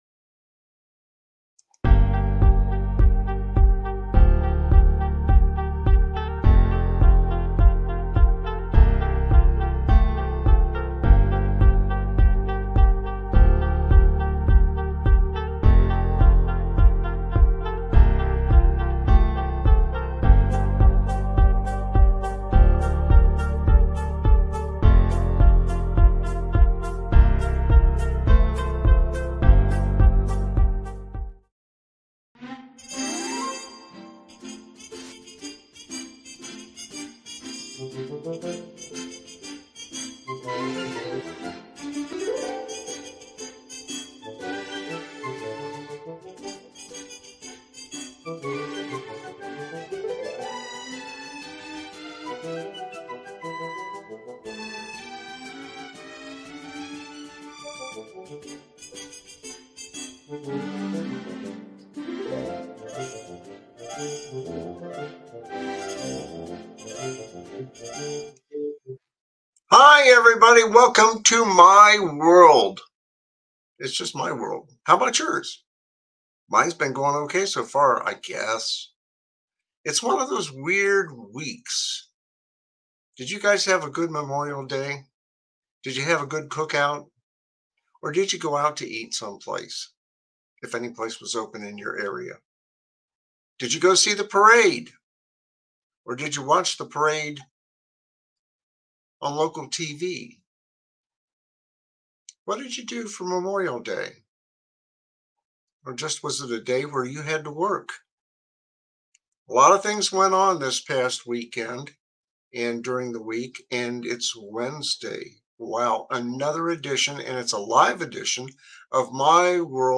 My WorldLive, Laff, Whatever is a satirical talk show that tackles the absurdities of life with ahealthy dose of humor.